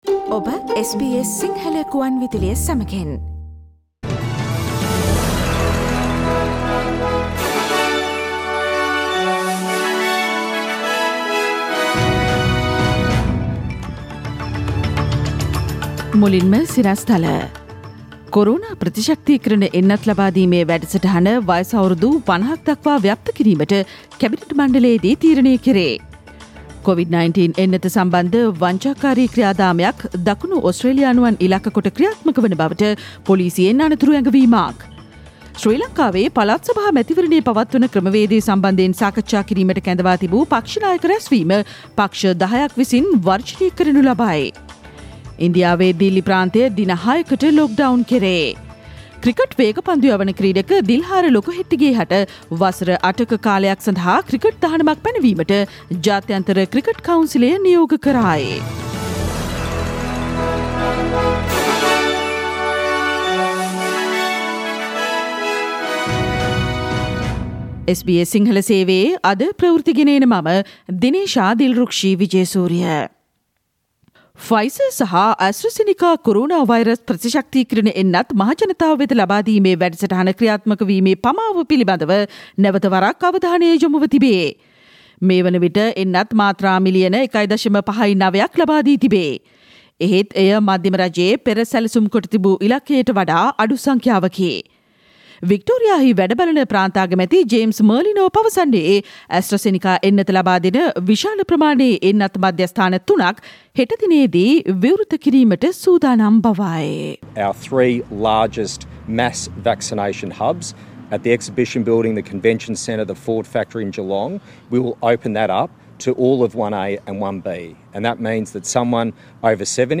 කොරෝනා එන්නත් වංචාකාරීන් සම්බන්දයෙන් දකුණු ඕස්ට්‍රේලියානුවන්ට අනතුරු ඇඟවේ: අප්‍රේල් 20දා SBS සිංහල ප්‍රවෘත්ති ප්‍රකාශය